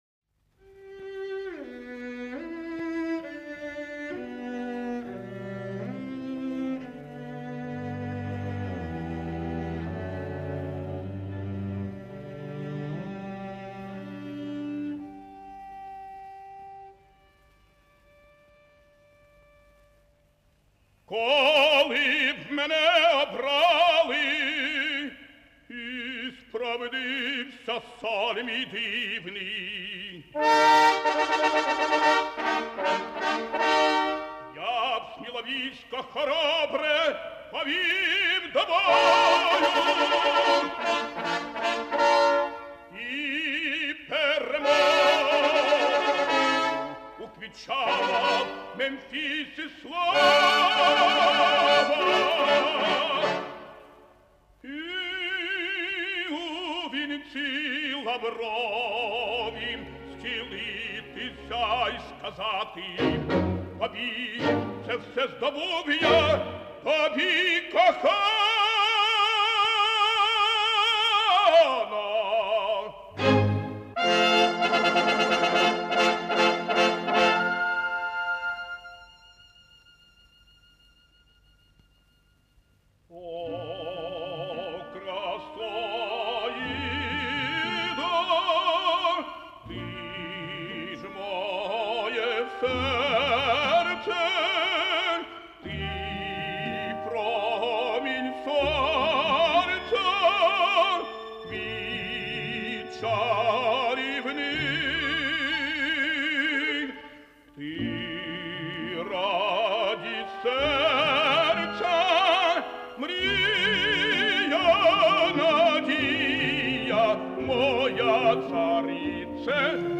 Опера «Аида». Романс Радамеса. Оркестр Киевского государственного театра оперы и балета.